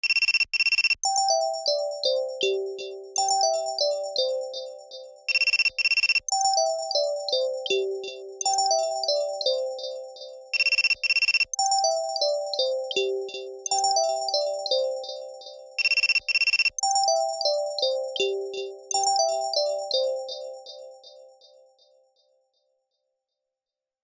Darmowe dzwonki - kategoria Budzik